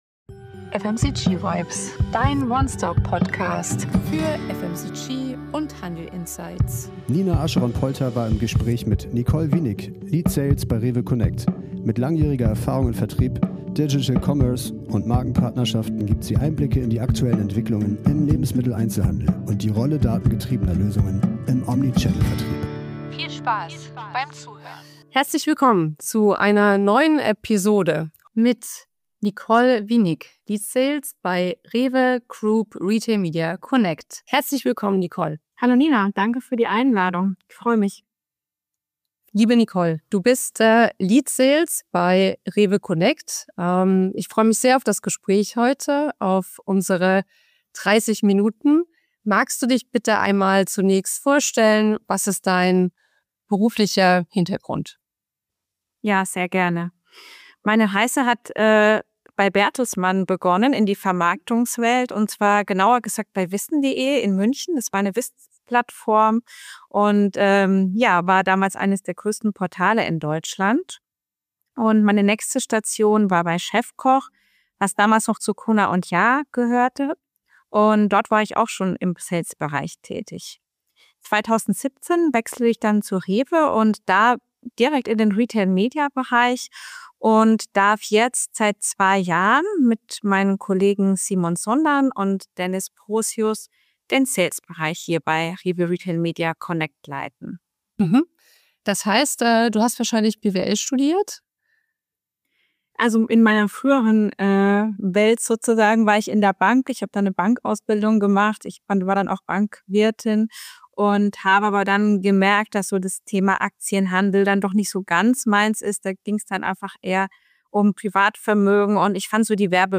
Expertinnentalk